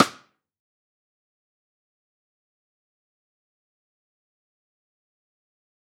D’Angelo Rim
DAngelo-Rim.wav